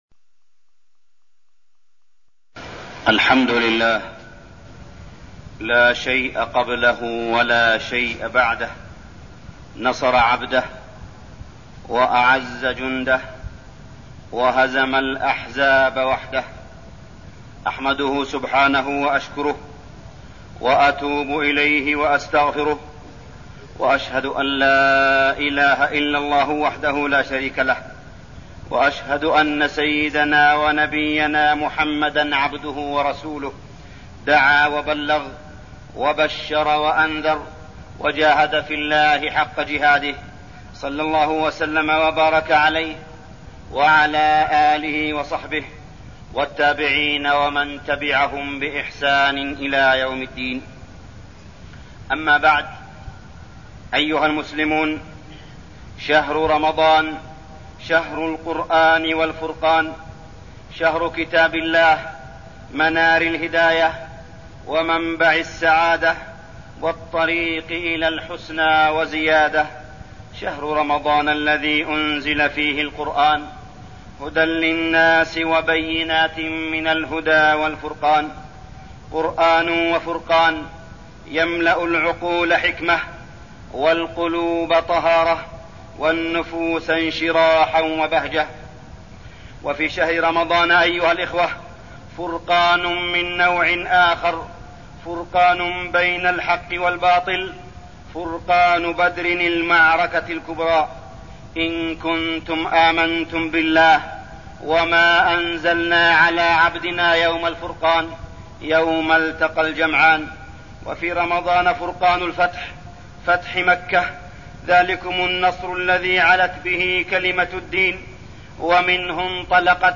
تاريخ النشر ١٨ رمضان ١٤١٠ هـ المكان: المسجد الحرام الشيخ: معالي الشيخ أ.د. صالح بن عبدالله بن حميد معالي الشيخ أ.د. صالح بن عبدالله بن حميد غزوة بدرالكبرى